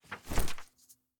页面转换声.wav